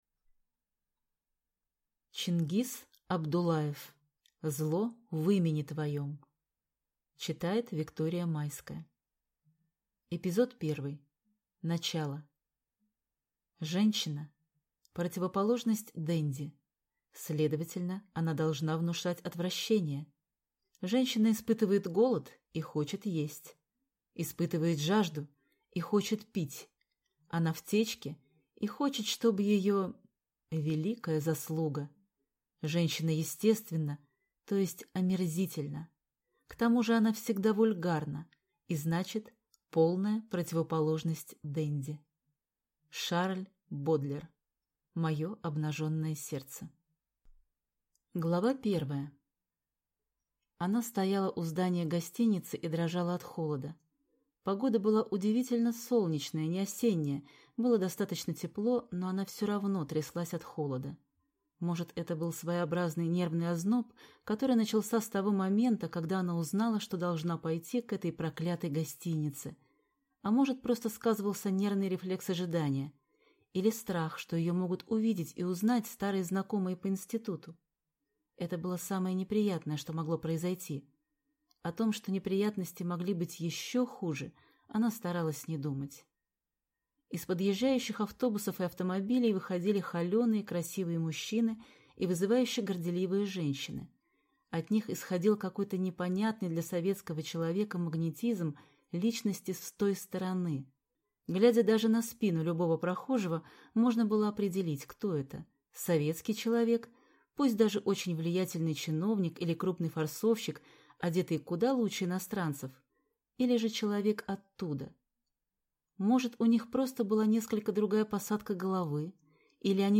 Аудиокнига Зло в имени твоем | Библиотека аудиокниг